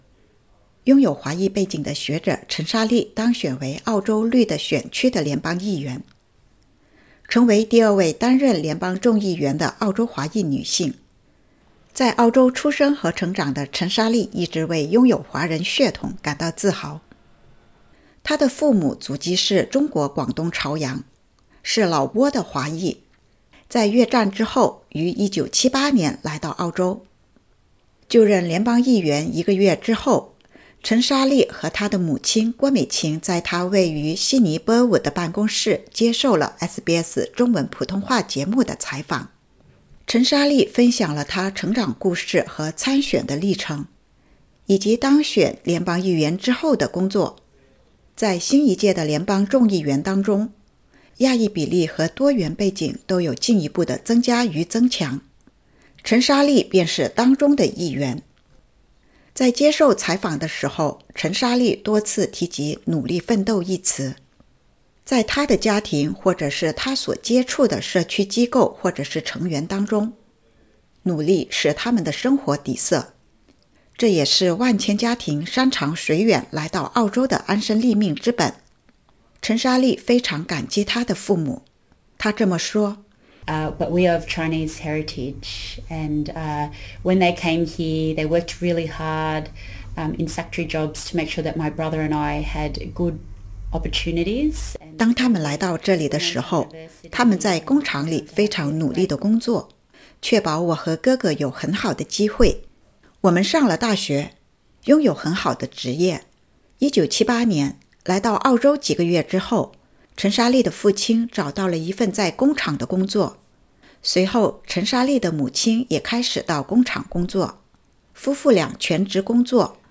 【专访】陈莎莉：从华裔学者到澳洲国会议员 “带来不同的经验和观点”